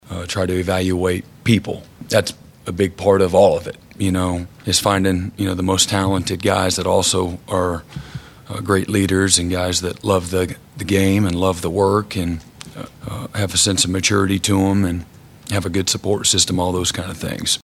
Oklahoma head football coach Brent Venables took the podium for OU’s first preseason press conference on Tuesday.